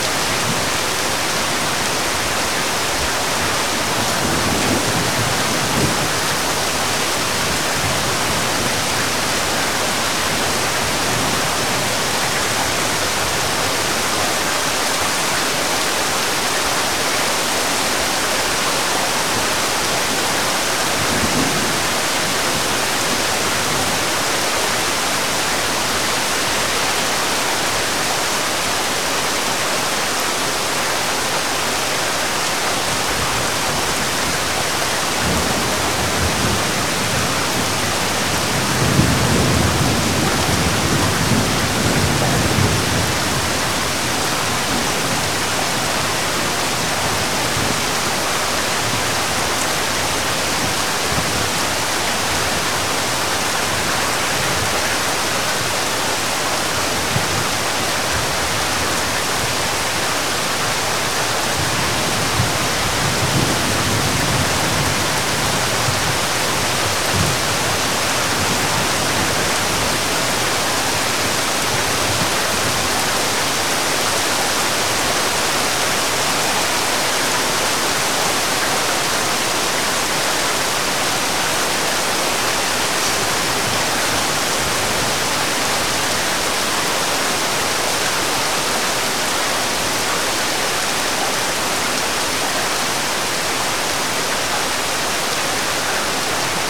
rain-2.ogg